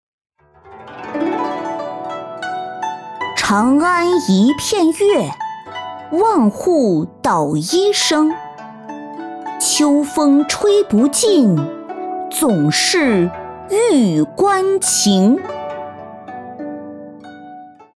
仿男童-女5-男童古诗.mp3